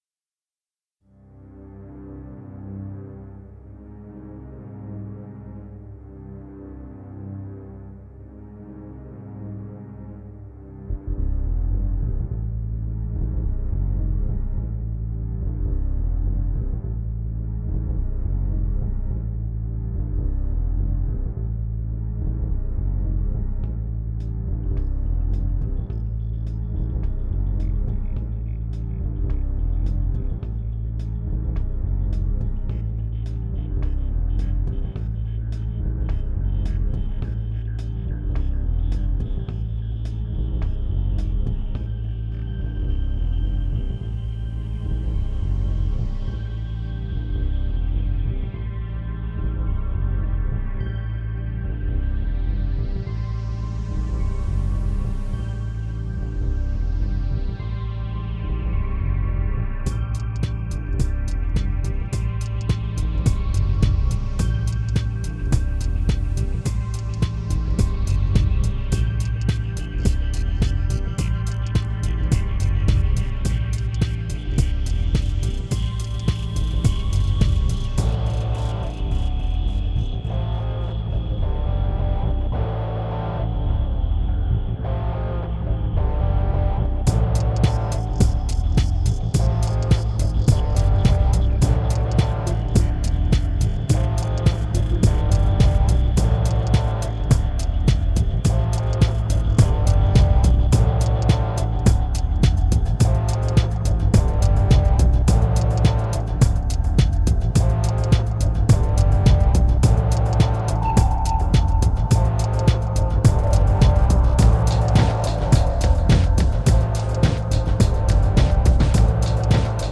2369📈 - -43%🤔 - 106BPM🔊 - 2011-01-28📅 - -430🌟